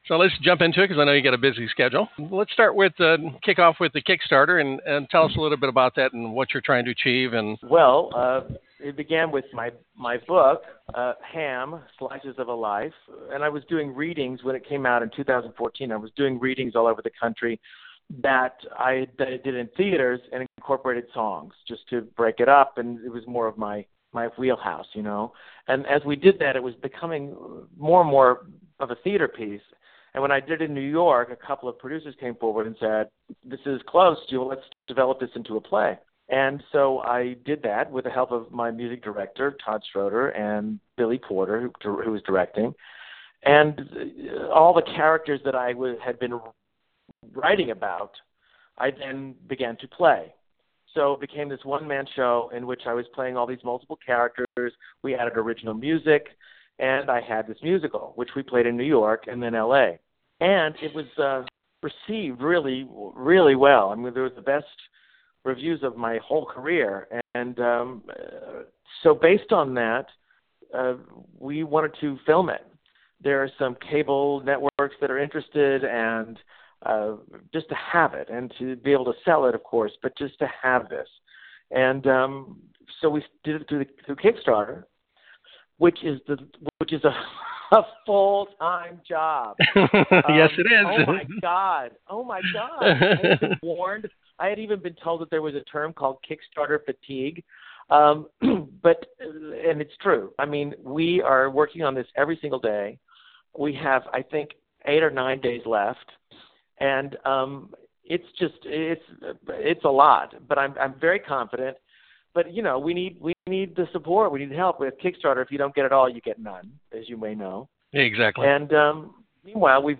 Interview with Sam Harris About Kickstarter